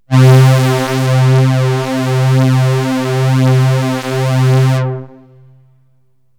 STRINGS 0009.wav